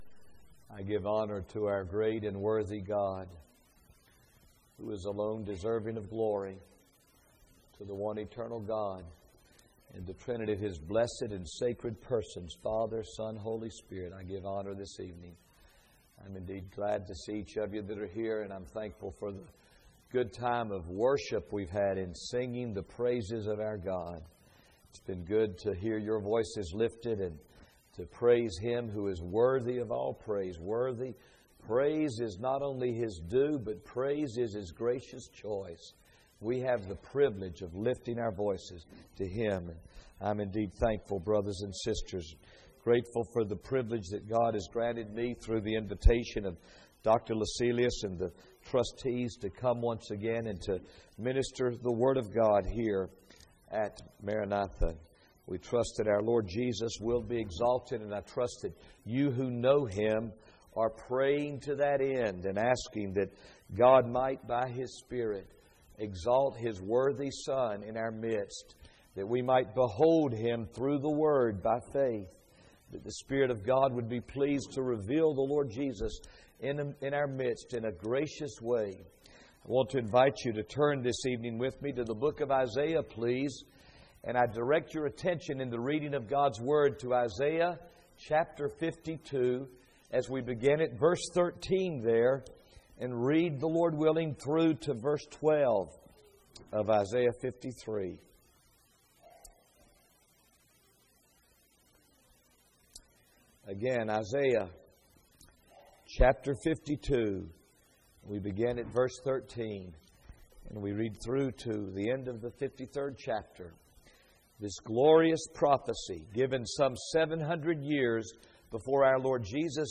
Series: 2013 July Conference Session: Evening Session